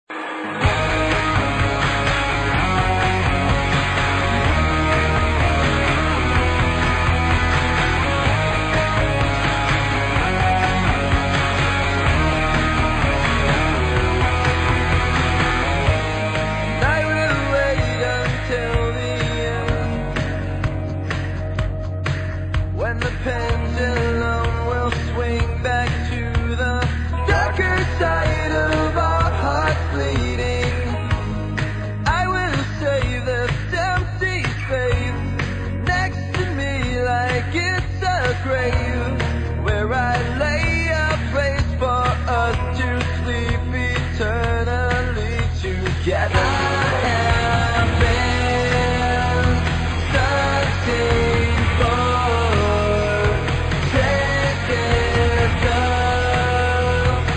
Главная страница>>Скачать mp3>>Рок рингтоны